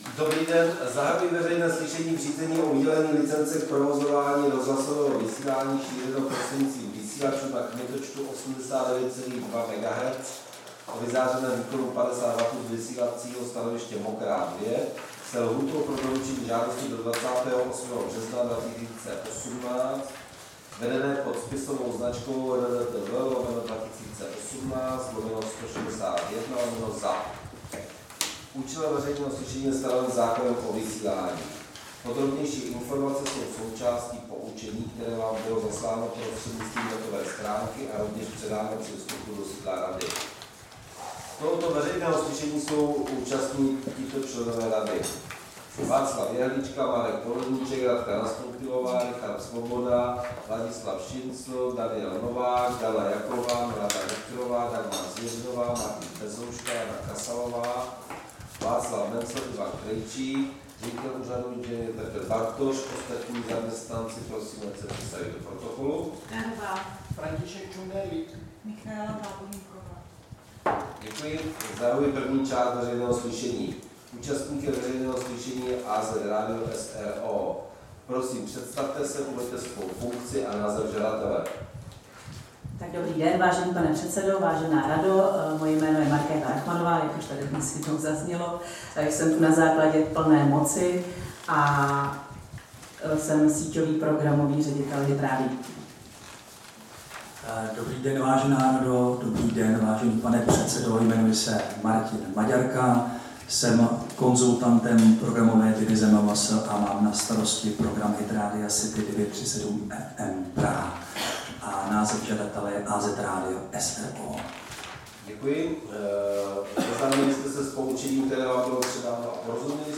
Veřejné slyšení v řízení o udělení licence k provozování rozhlasového vysílání šířeného prostřednictvím vysílačů se souborem technických parametrů Mokrá 89,2 MHz/50 W
Místem konání veřejného slyšení je sídlo Rady pro rozhlasové a televizní vysílání, Škrétova 44/6, PSČ 120 21, Praha 2 Vinohrady.